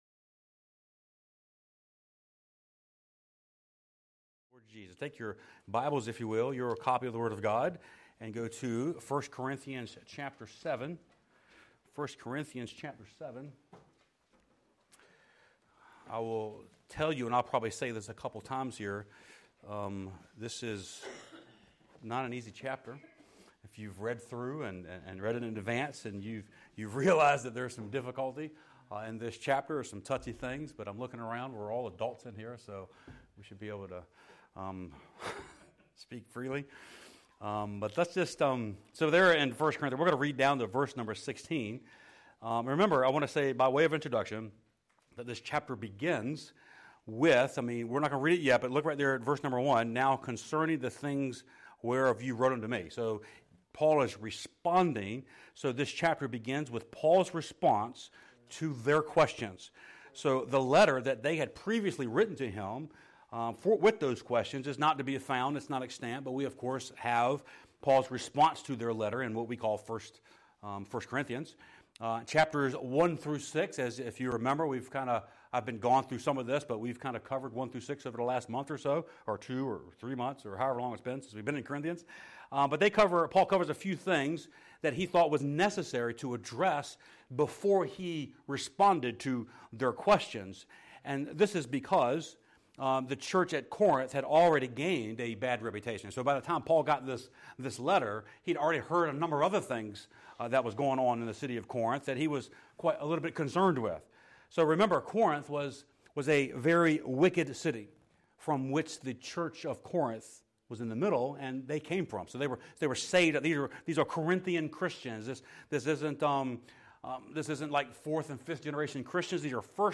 Sermons | Hohenfels Baptist Church